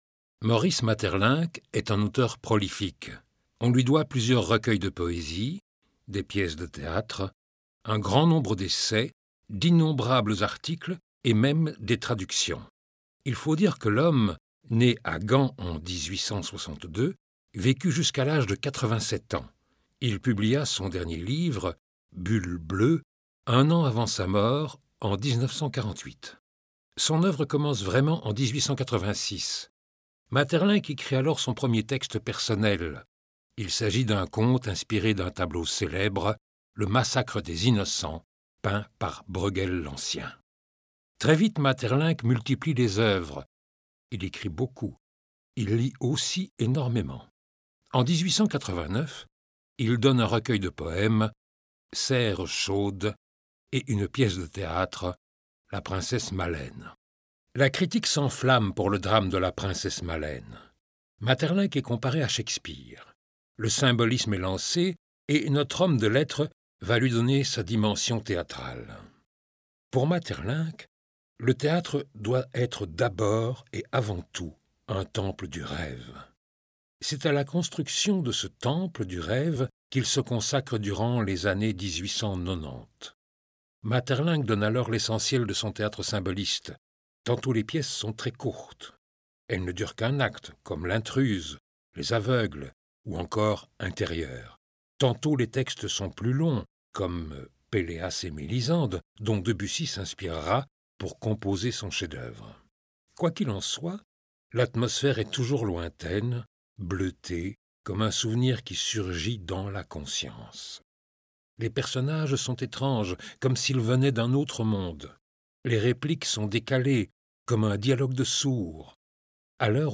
Extrait de l'audioguide en mp3
Audioguide.mp3